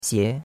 xie2.mp3